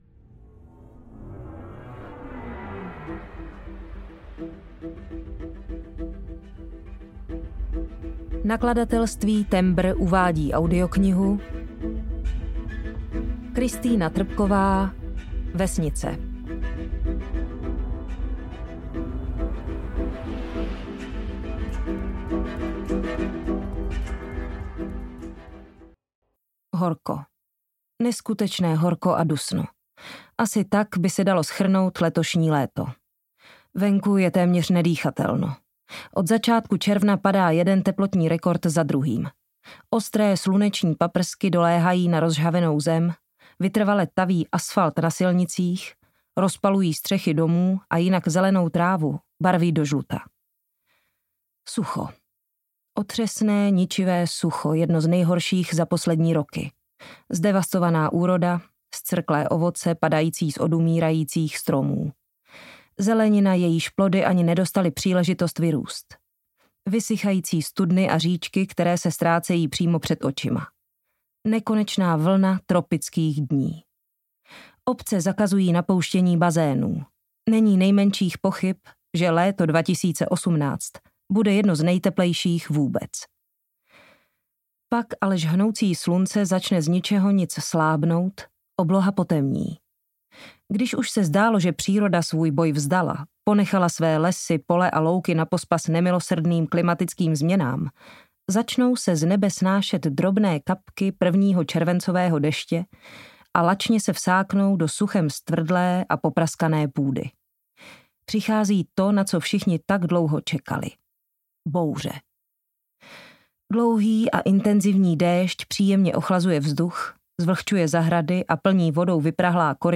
Vesnice audiokniha
Ukázka z knihy
• InterpretZuzana Kajnarová